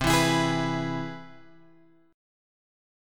C Augmented Major 7th